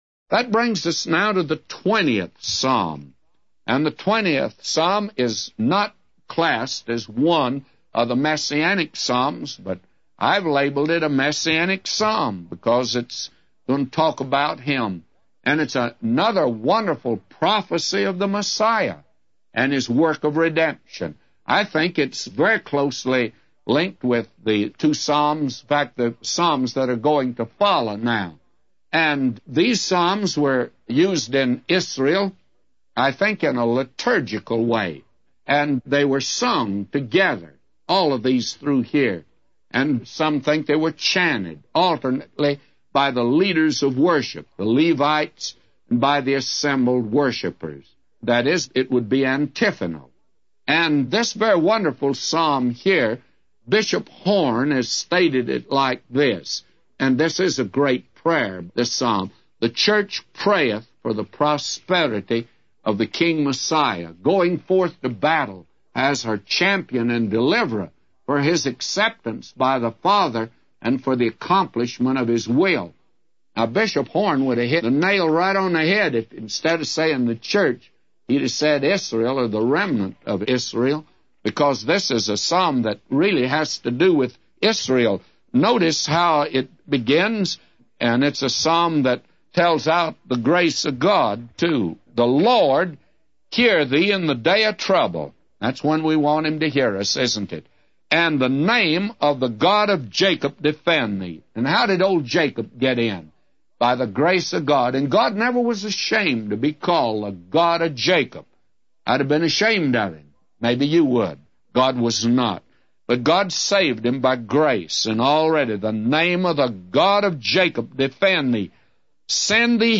A Commentary By J Vernon MCgee For Psalms 20:1-999